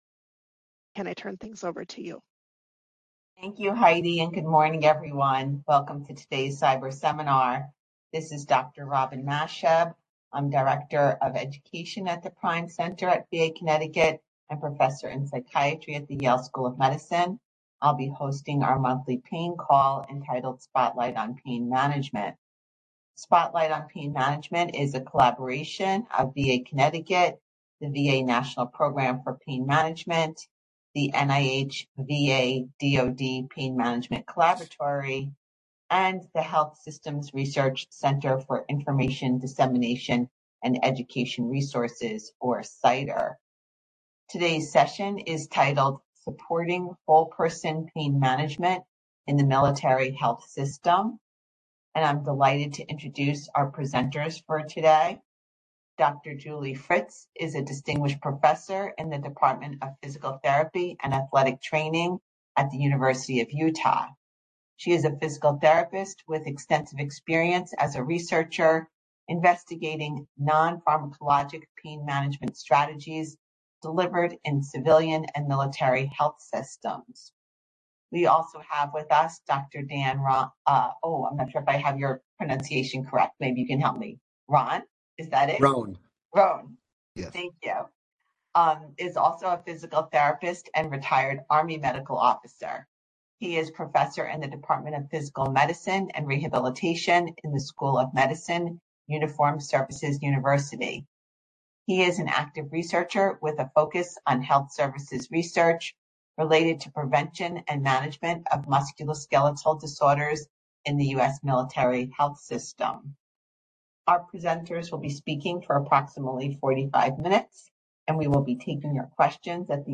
The Military Health System is supporting a shift towards more holistic, whole-person, approaches to pain management. The presenters of this seminar have been researching strategies to implement holistic pain management strategies in the Military Health System and evaluating ways to provide pain care that aligns with the DoD/VA Stepped Care Model for Pain Management.